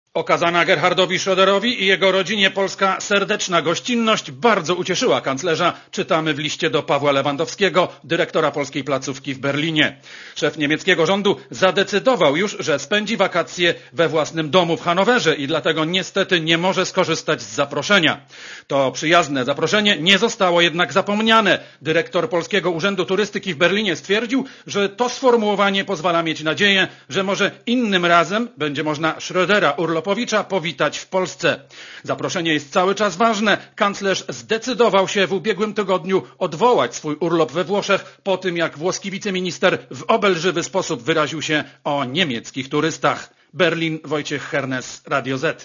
Źródło: PAP (RadioZet) Źródło: (RadioZet) Korespondencja z Berlina Oceń jakość naszego artykułu: Twoja opinia pozwala nam tworzyć lepsze treści.